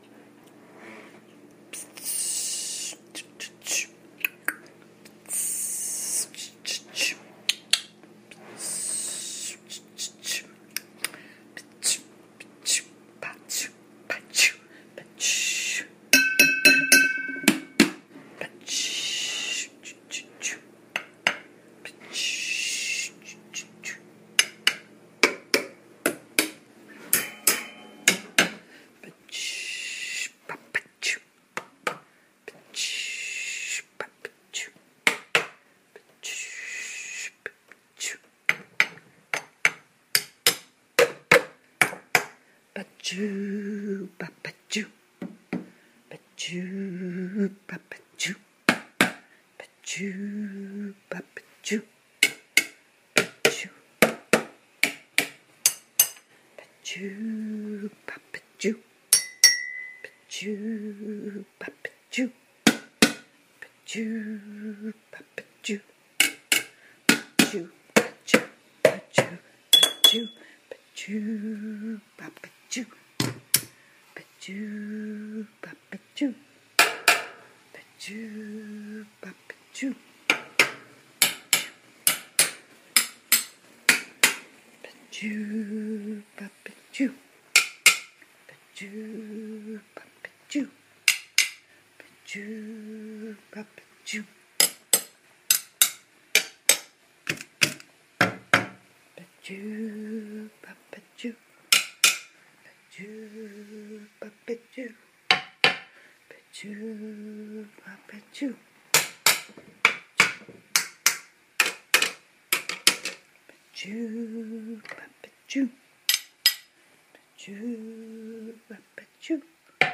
Everyday Objects as Musical Instruments
Music can add sweetness to our life, this audio is of me playing with Spoon Around The House- picking up unique vibrations. Tones are a way to acknowledge that music is all around us all the time in our everyday life.
If you fast forward toward the end you will hear the tones of some actual musical instruments.